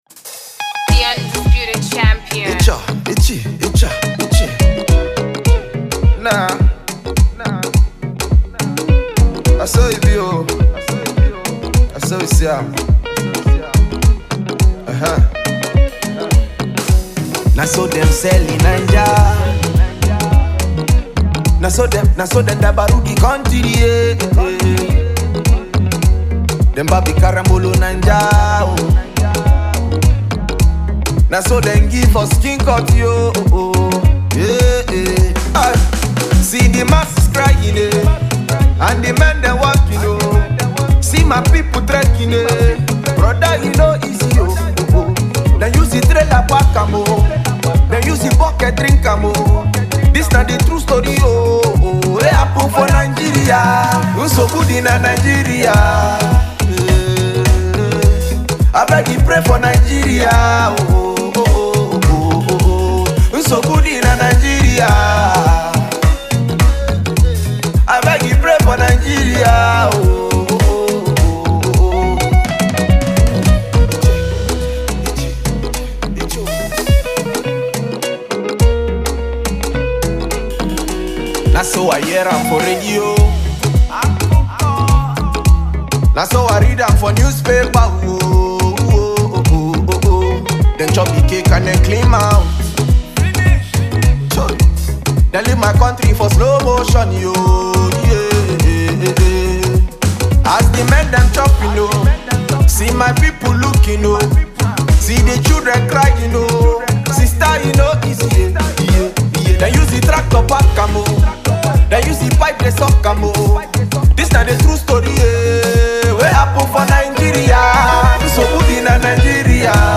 highlife tune